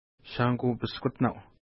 Pronunciation: ʃa:nku:-pəskutna:w